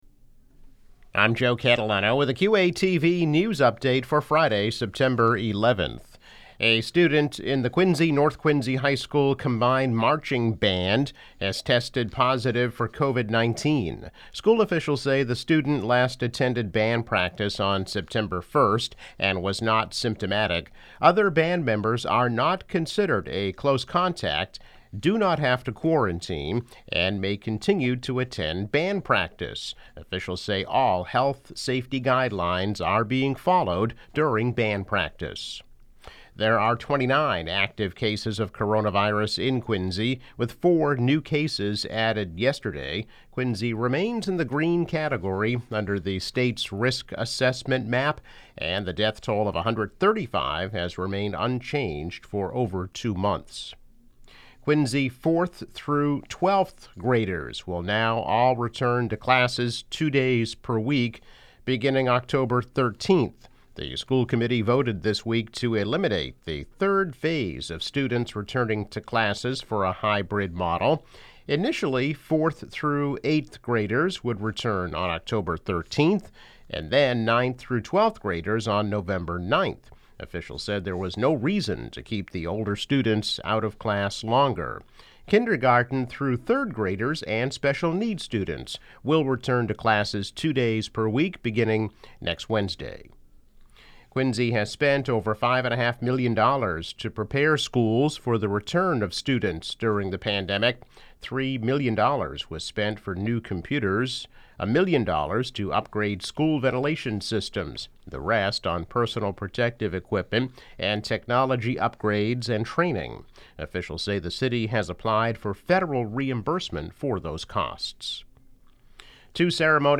News Update - September 11, 2020